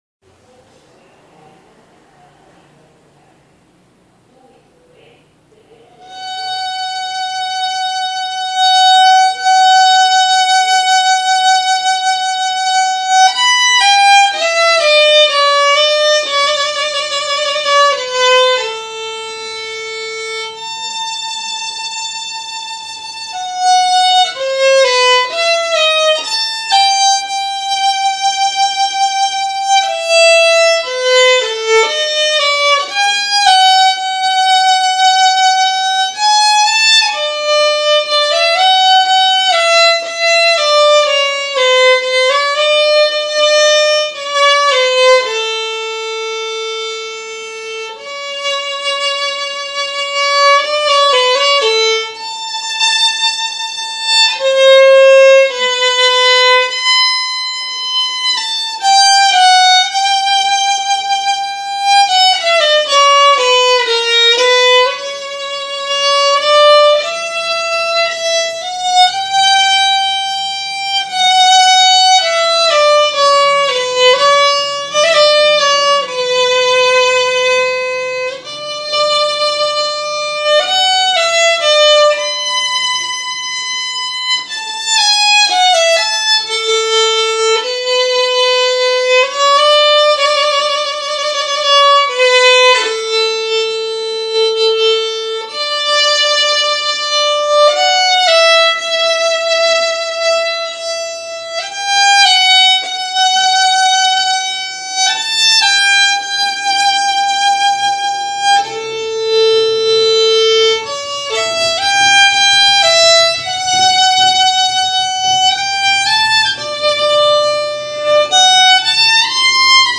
Hi folks here i've recorded myself practicing this classical piece.